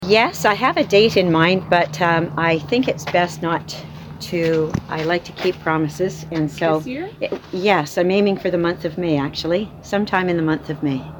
Pontiac Warden Jane Toller held a small press conference on Monday morning (April 4) to announce that she has purchased the Cinéma Lyn, located in downtown Fort-Coulonge at 526 rue Baume.